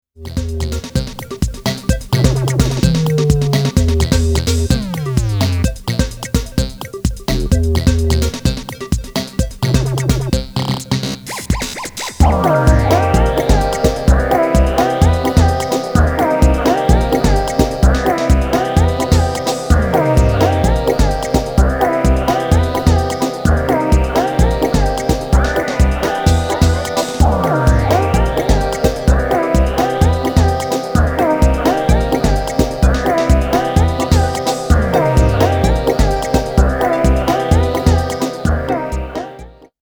コンピュータライズド期のダンスホールのオモチャ感覚溢れるチープ
なシンセを多用しながらも、ジョグリン的ビート、そして独特の浮遊感を